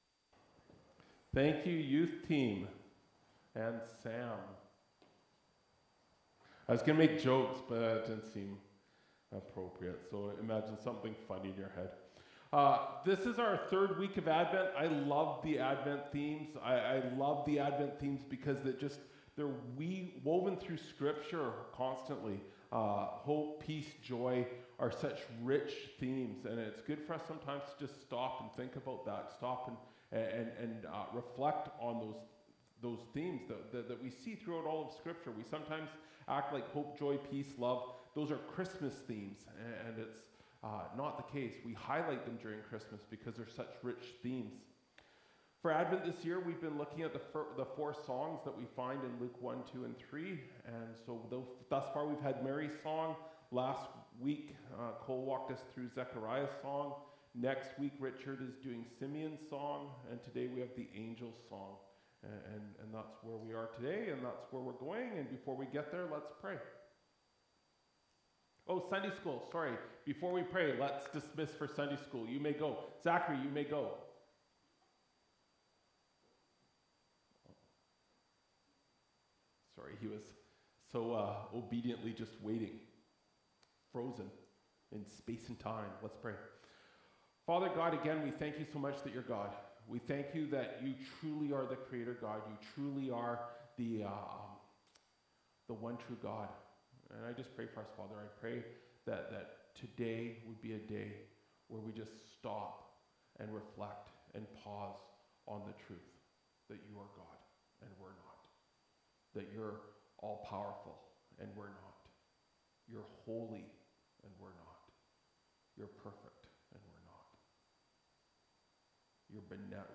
Dec 15, 2024 The Angels’ Song (Luke 2:1-20) MP3 SUBSCRIBE on iTunes(Podcast) Notes Discussion Sermons in this Series Loading Discusson...